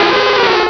Cri de Lokhlass dans Pokémon Rubis et Saphir.
Cri_0131_RS.ogg